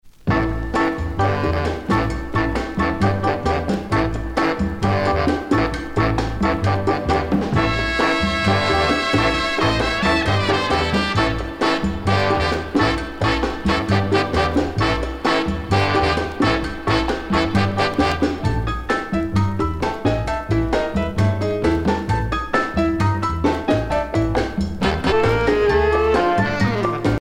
danse : cha cha cha